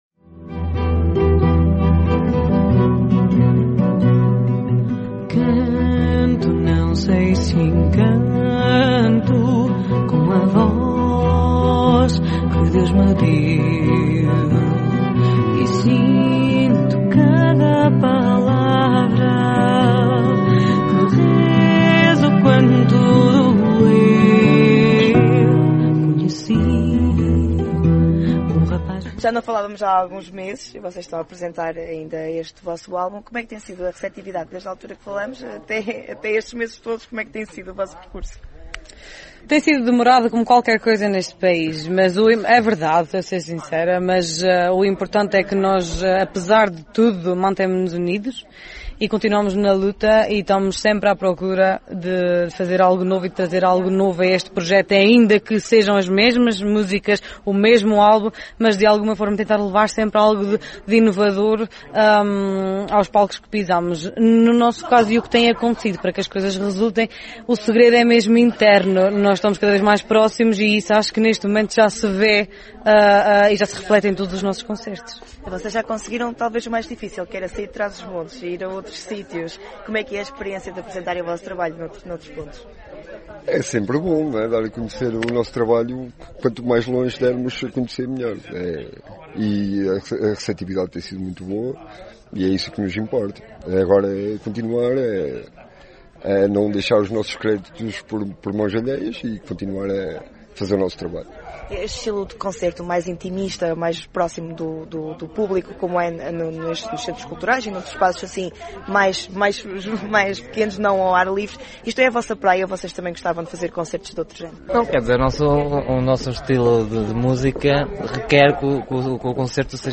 Entrevista_Lacre.mp3